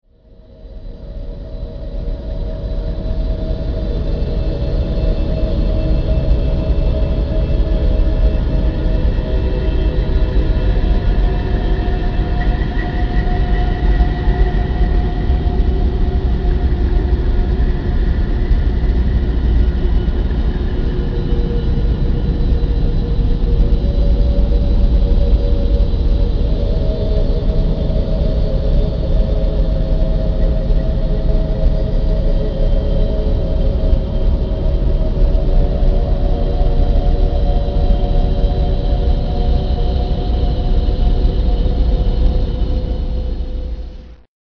Звуки Земли в необычных частотах почти как песня